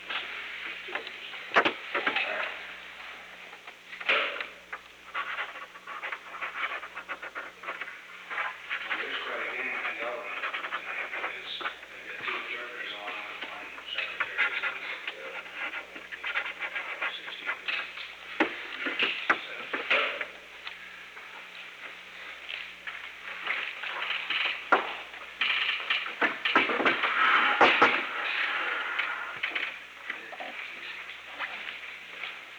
Secret White House Tapes
Conversation No. 735-9
Location: Oval Office
The President met with Alexander P. Butterfield.